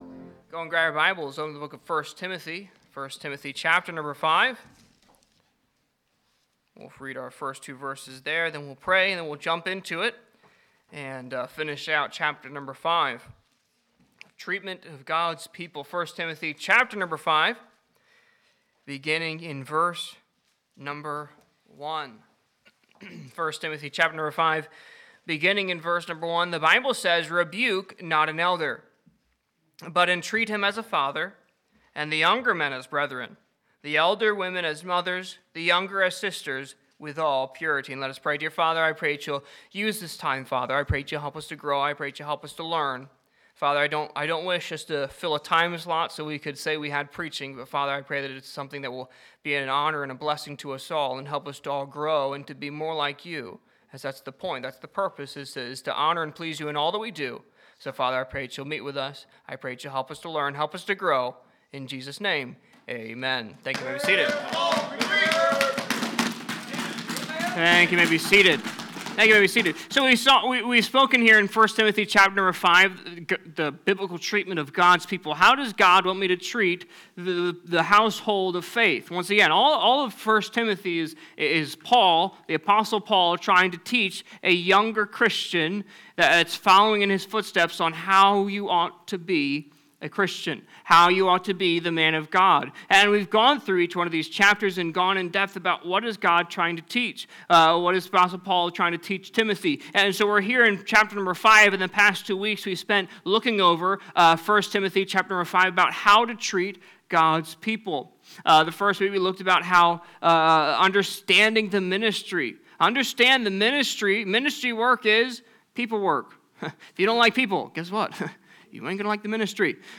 Sermons preached from the pulpit of Anchor Baptist Church in Columbus, Ohio.